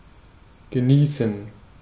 Ääntäminen
US Tuntematon aksentti: IPA : /ɹɪˈsiːv/